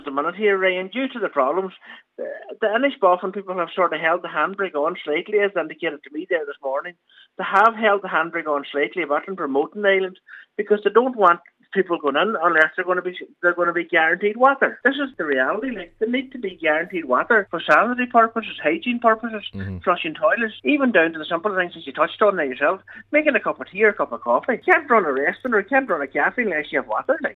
Cllr Michael McClafferty says groups are reluctant to advertise the island given the issues that exist: